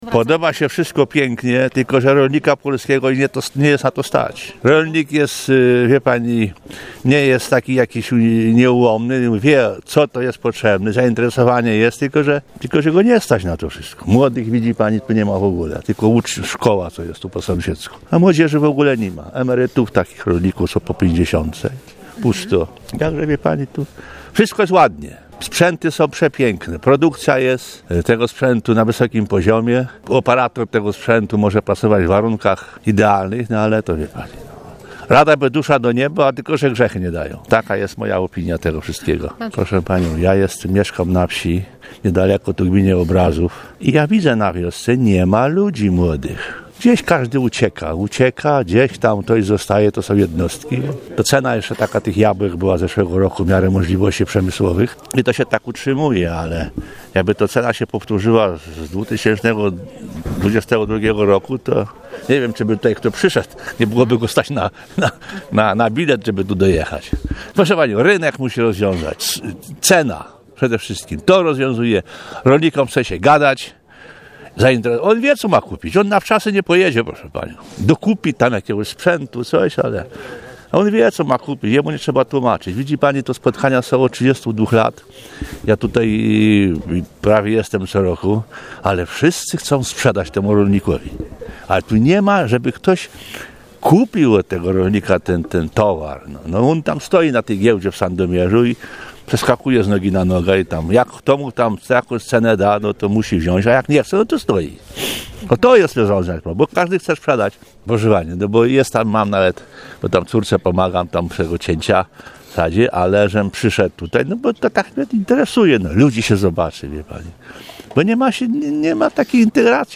W hali MOSiR w Sandomierzu rozpoczęło się dziś 32. Spotkanie Sadownicze.